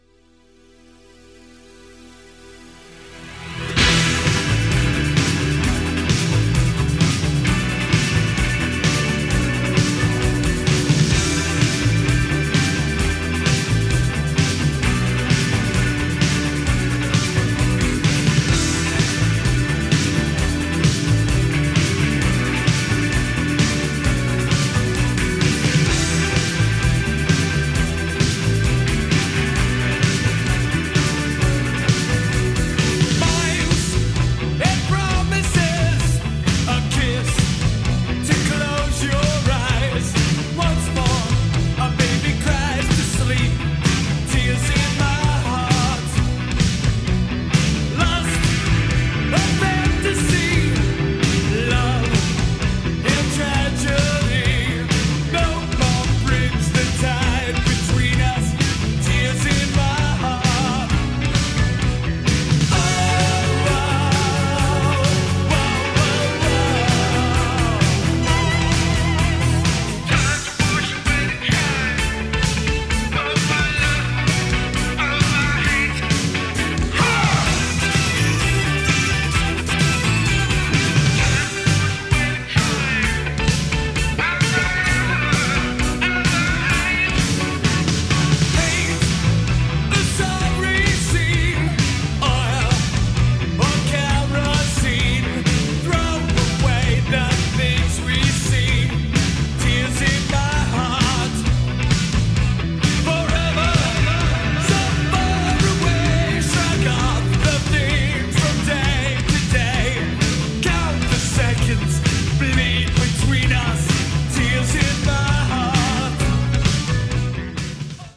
A Collection of 1980s Jersey Rock tracks.